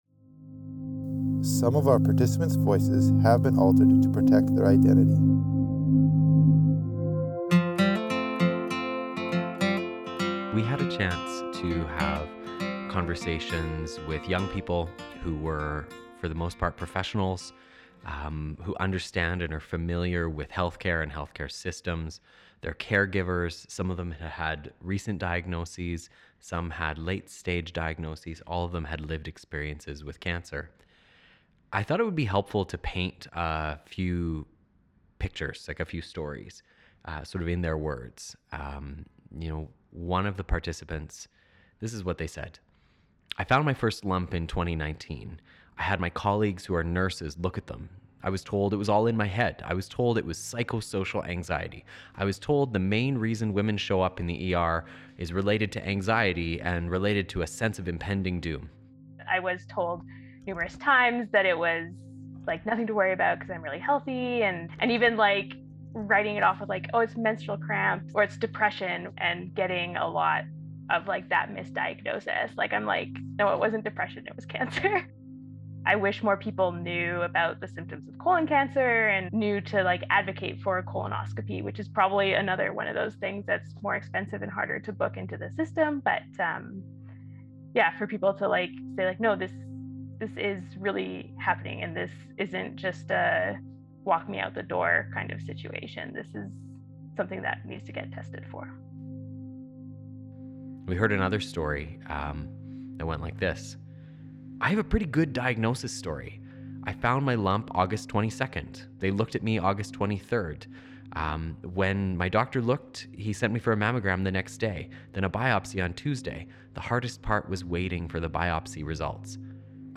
To solve this, we had the facilitators who had conducted the interviews sit down together in a makeshift podcast studio and talk through what they had heard.
So we recut the series, weaving in patient audio from recorded interviews.